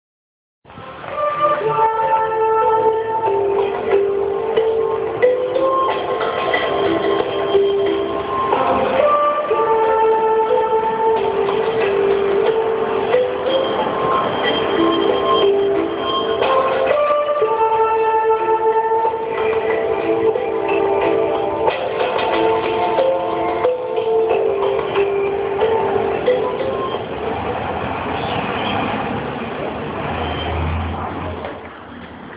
Gas truck delivering in São Paulo, Brasil